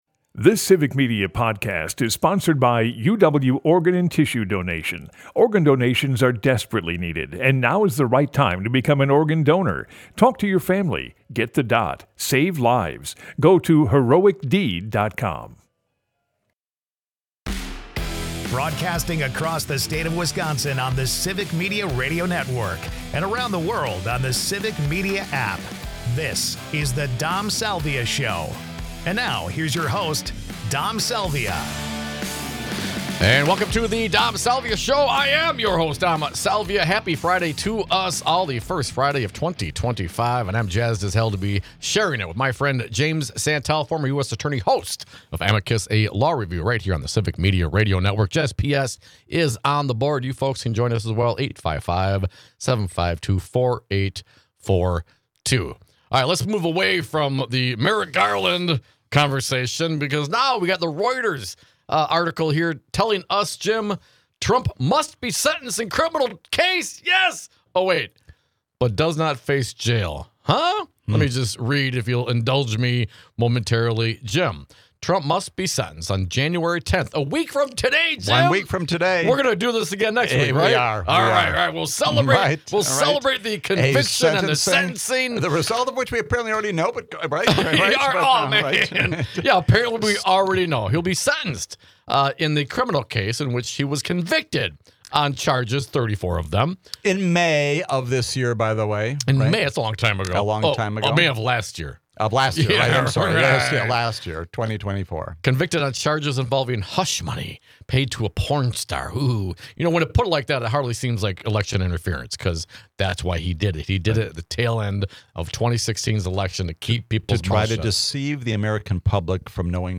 (Hour 2) Guests: Jim Santelle 1/3/2025 Listen Share Jim Santelle joins us as cohost on this first Friday of the New Year. Finally, finally , we know the day when Donald Trump will face sentencing for his election interference related to fraudulent financial documents in the state of New York.